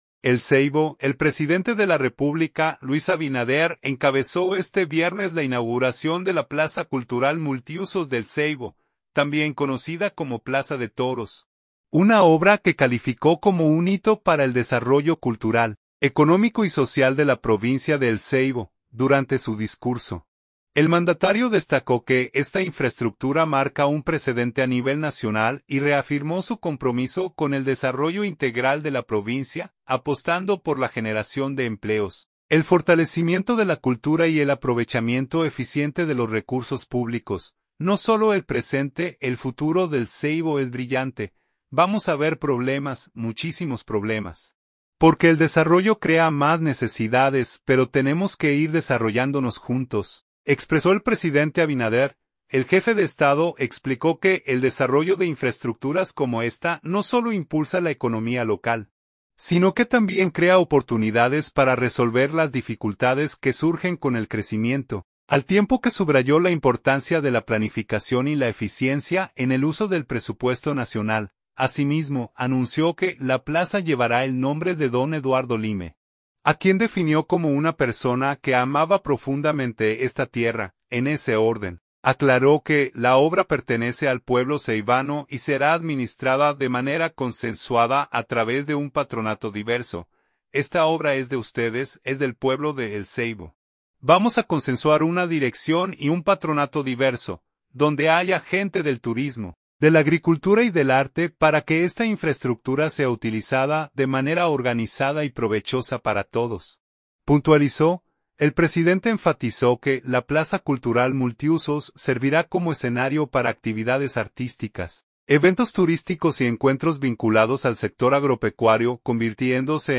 Presidente Abinader en inauguración de Plaza de Toros: “El futuro de El Seibo es brillante”